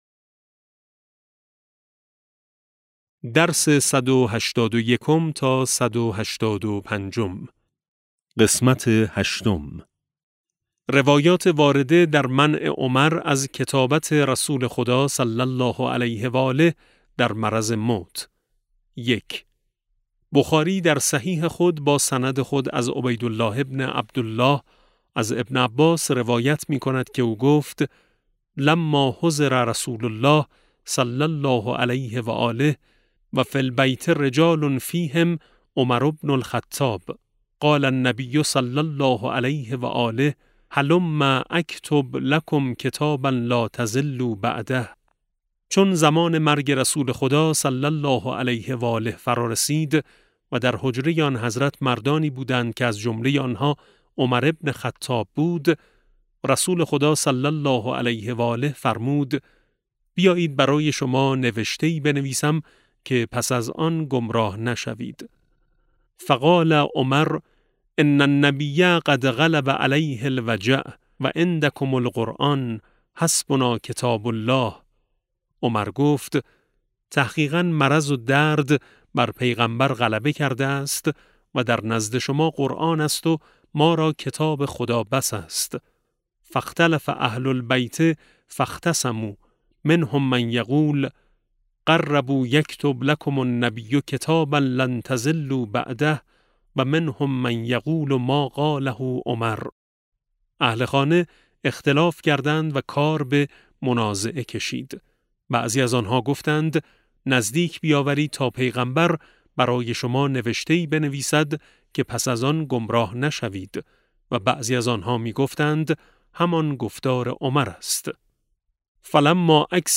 کتاب صوتی امام شناسی ج 13 - جلسه8